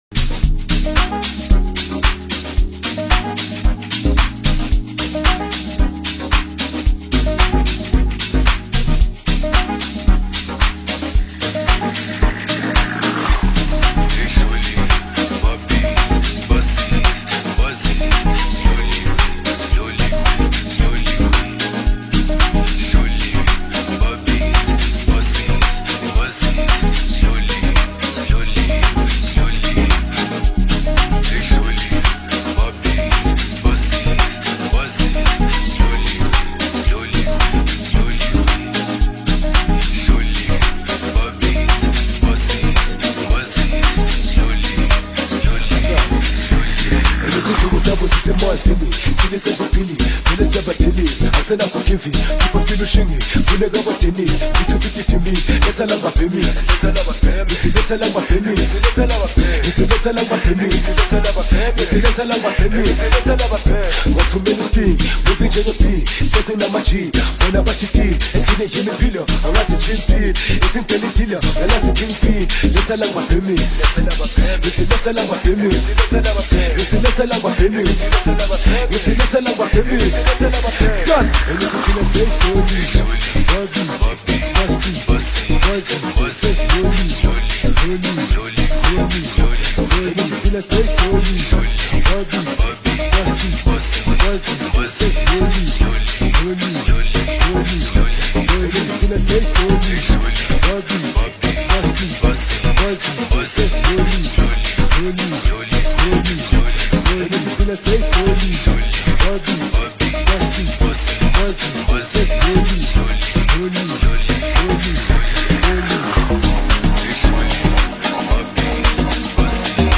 Song Genre: Amapiano Song.